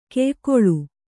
♪ keykołu